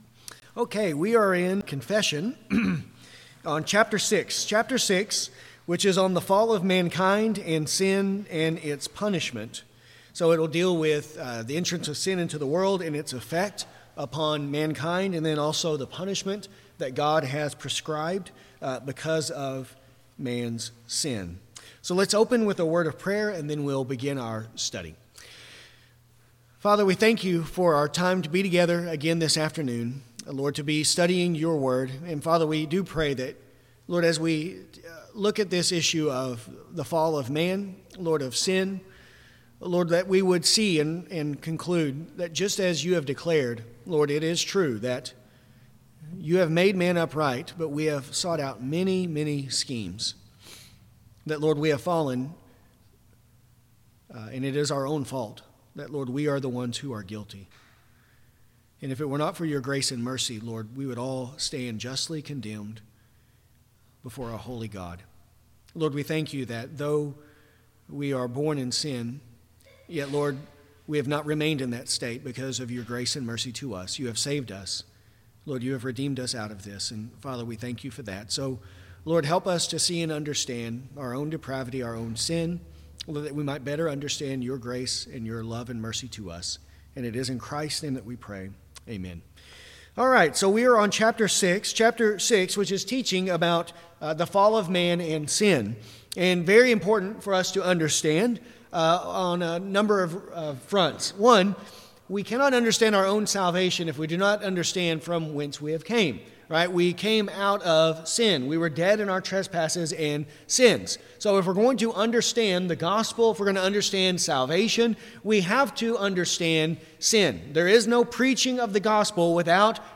This lesson covers paragraphs 6.1-6.2 .